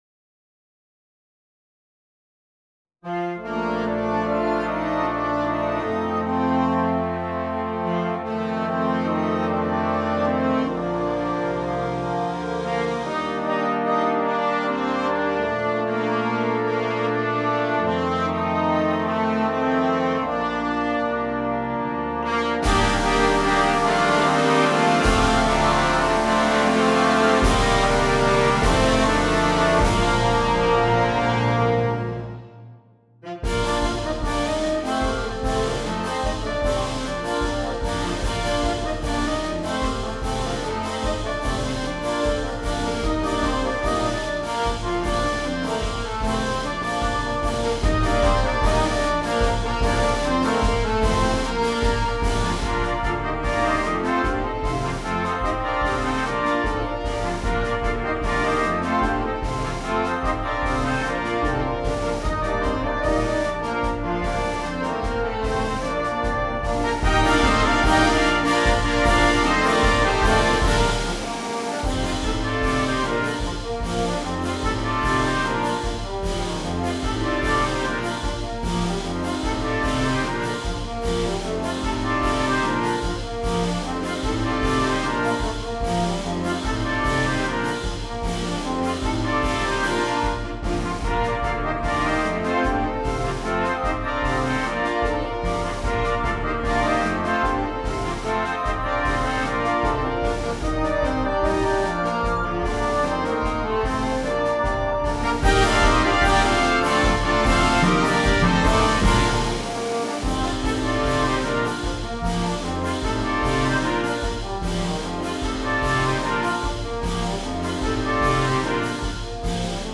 • Flauta 1
• Clarinete en Bb 1
• Clarinete Bajo
• Saxofón Alto 1
• Saxofón Tenor
• Saxofón Barítono
• Trompeta en Bb 1
• Corno en F 1
• Trombón 1
• Tuba
• Timbal
• Platillos
• Redoblante
• Bombo